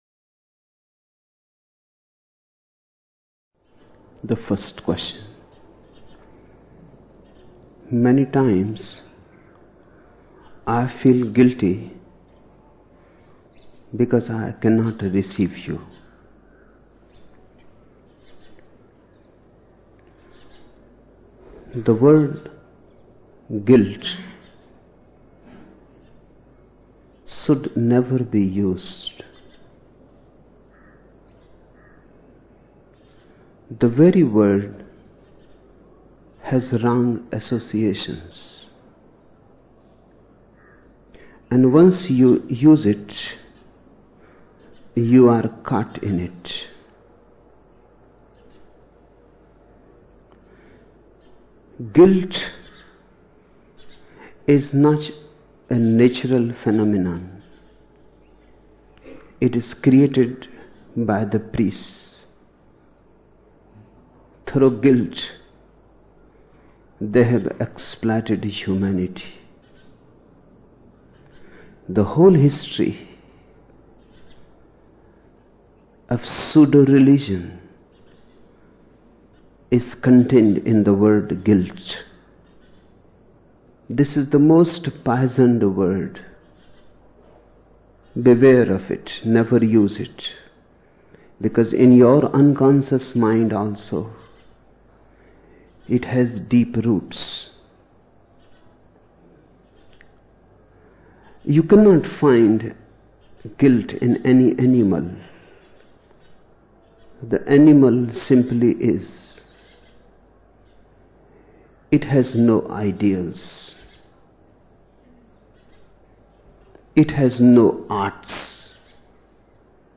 12 December 1975 morning in Buddha Hall, Poona, India